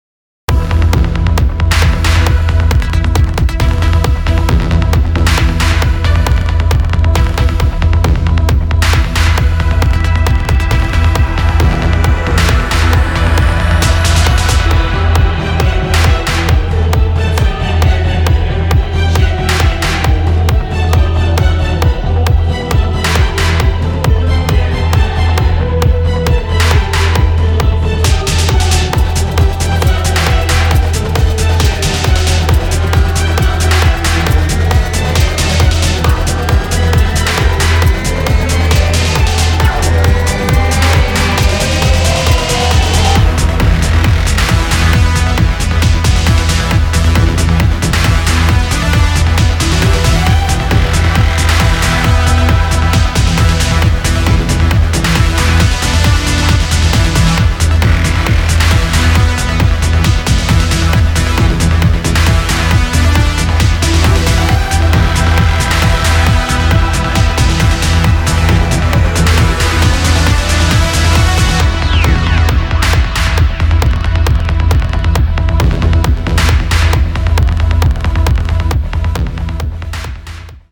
Cybertron Collection is an expansive and meticulously crafted collection of 1.200 cinematic presets for Reveal Sound Spire, designed to cover the full spectrum of modern cinematic, EDM, and sci-fi sound design.
• Cinematic and sci-fi inspired sound palette fused with modern EDM power
From delicate crystalline melodies to massive basses, evolving pads, powerful rhythms, and immersive FX, this collection gives you everything you need to craft bold, modern, and emotionally driven productions.
• * The video and audio demos contain presets played from Cybertron Collection sound bank, every single sound is created from scratch with Spire.